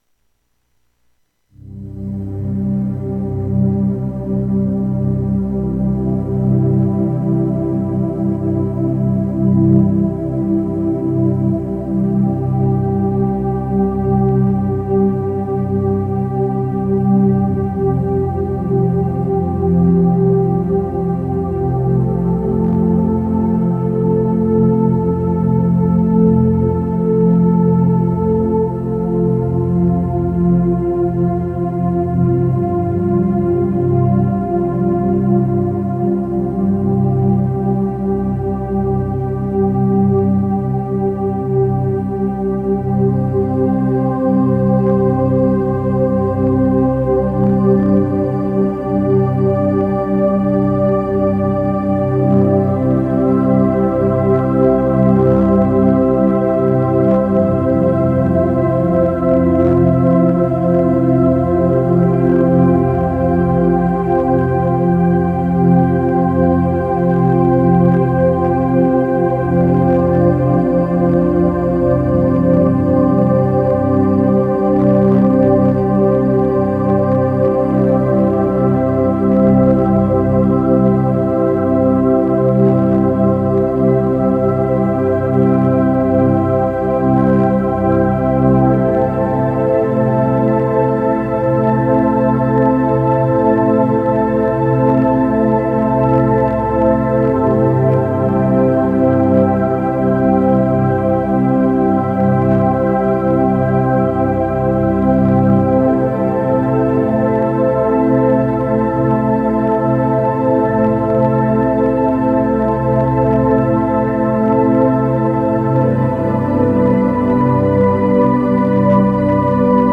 Eveil-Corpus-Musique-de-Guerison.mp3